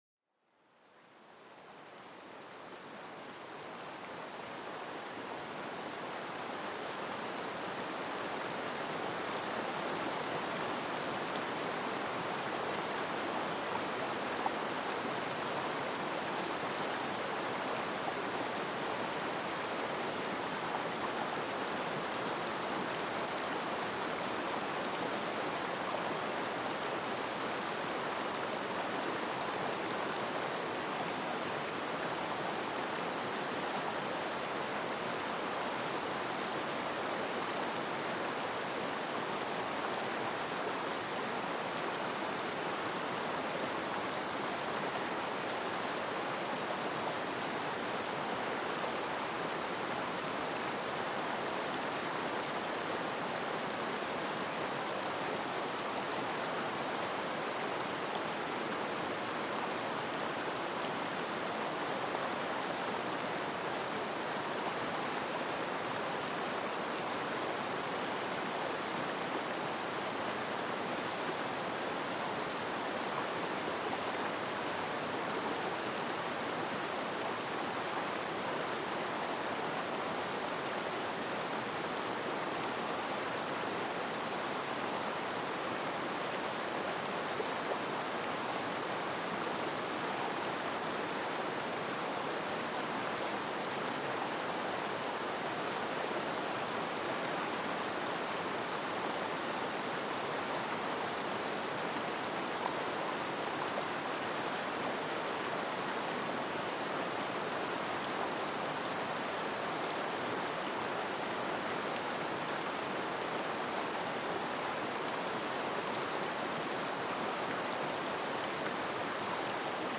Le doux murmure du courant pour apaiser l'esprit